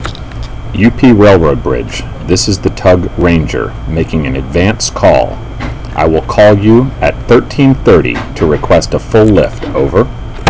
The UP Railroad Bridge tender listens on VHF FM Channel 13.
Towing vessel